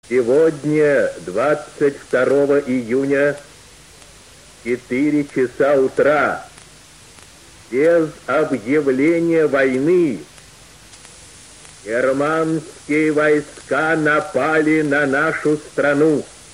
1_Golos_Yu._B._Levitana_-_Obyavlenie_o_nachale_vojny.mp3